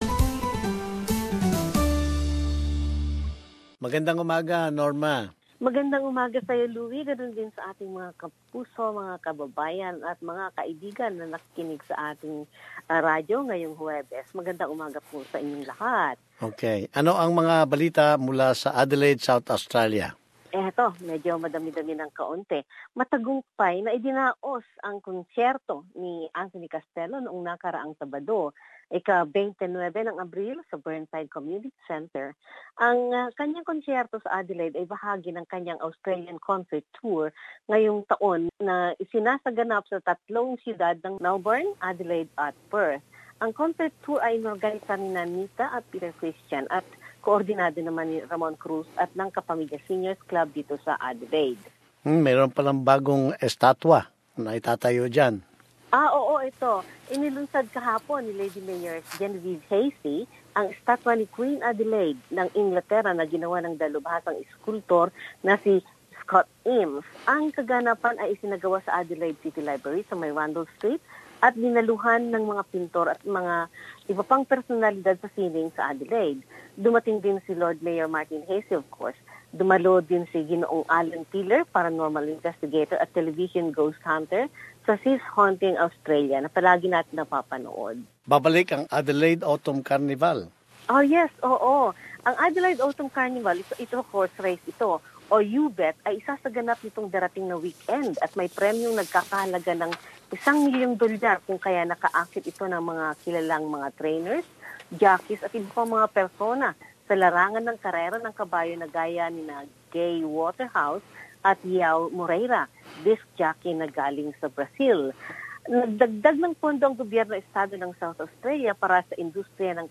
Mga bahagi ng mga balitang hatid sa atin